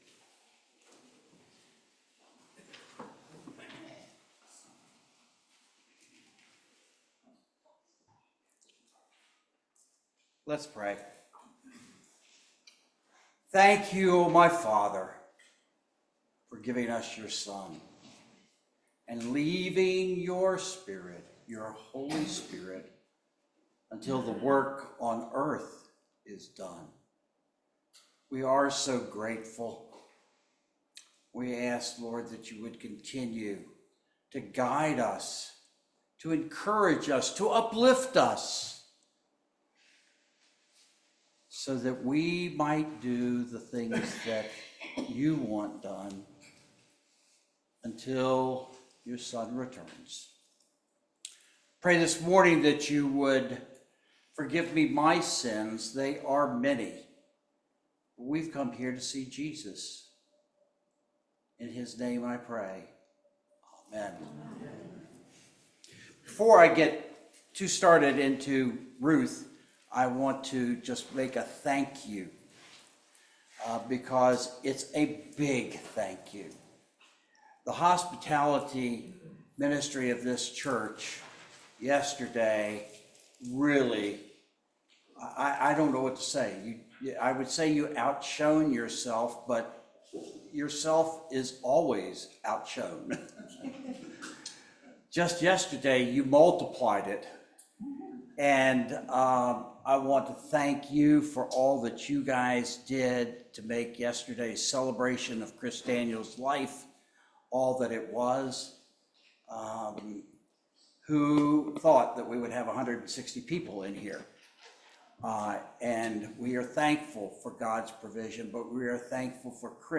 Grace Anglican Church